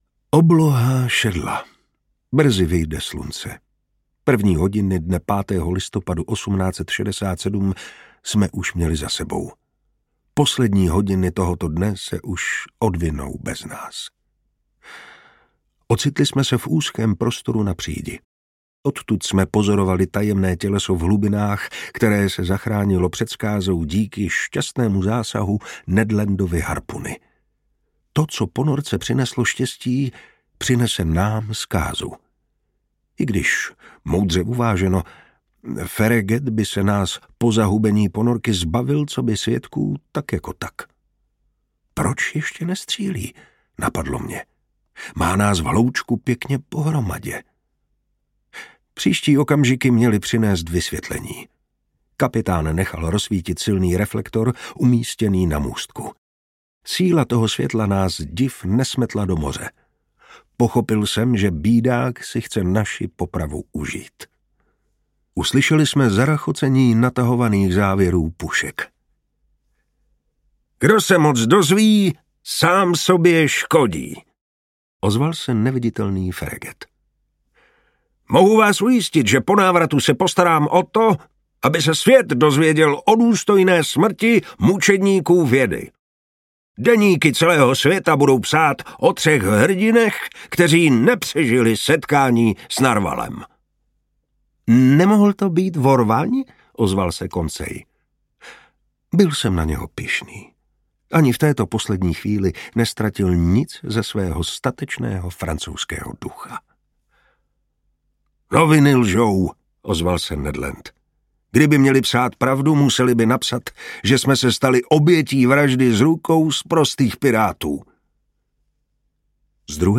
Audio kniha
Ukázka z knihy
• InterpretMartin Preiss